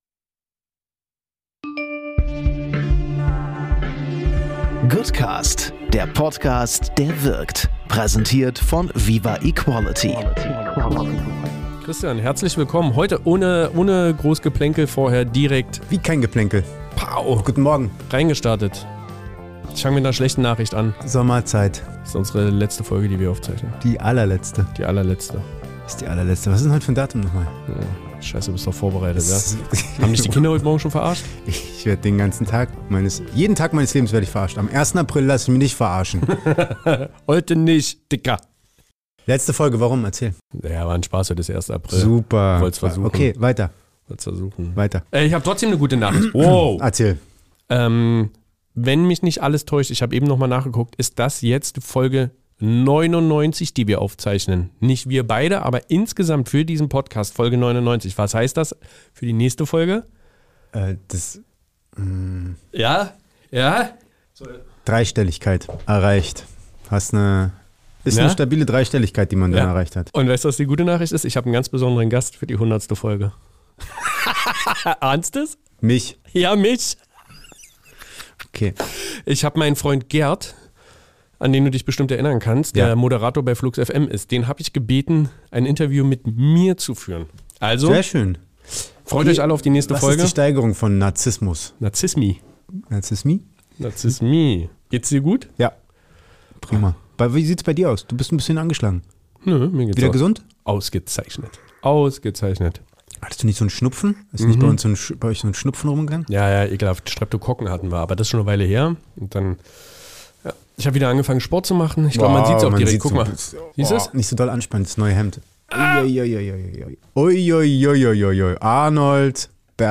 Es wird garantiert lustig!